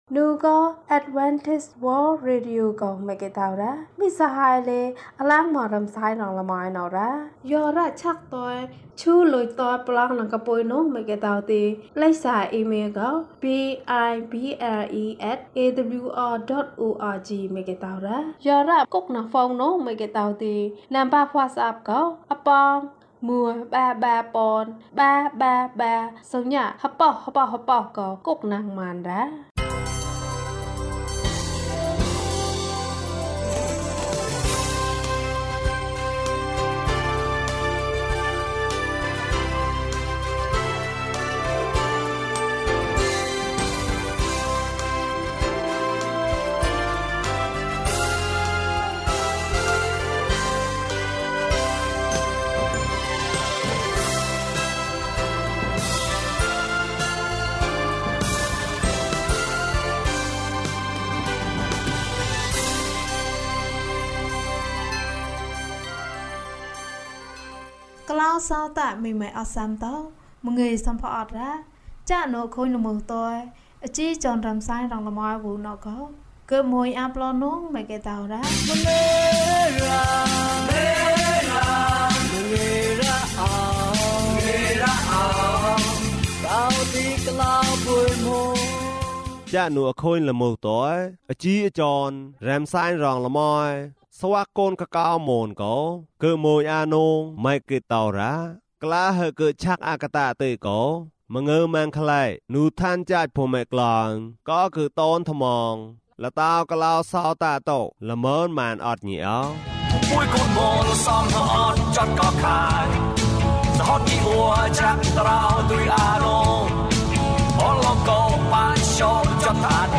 သင်၏ဘုရားသခင်၏ဆု။ ကျန်းမာခြင်းအကြောင်းအရာ။ ဓမ္မသီချင်း။ တရားဒေသနာ။